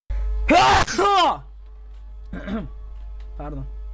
Play, download and share HAPŞIRMA! original sound button!!!!